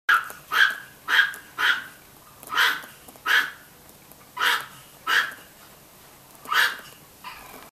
Tiny Dog Barking Sound Effect Download: Instant Soundboard Button
Tiny Dog Barking Sound Button - Free Download & Play